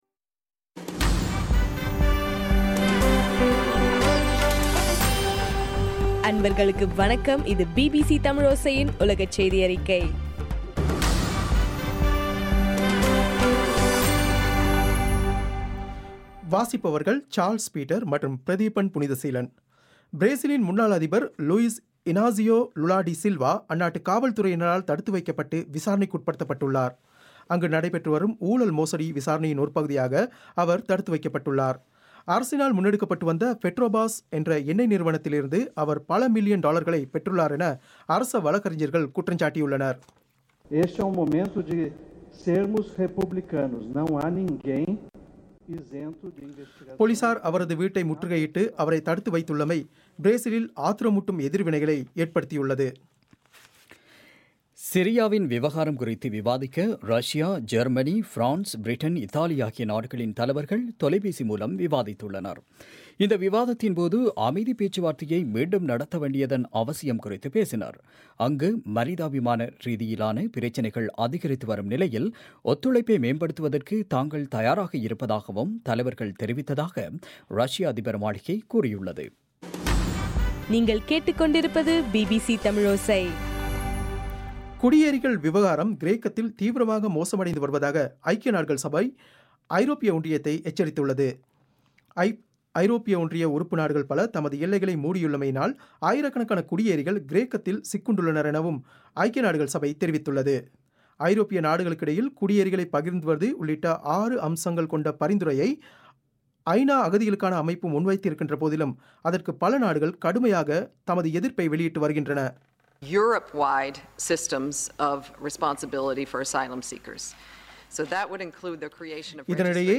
பிபிசி தமிழோசை- உலகச் செய்தியறிக்கை- மார்ச் 04